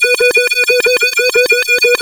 OSCAR 14 B1.wav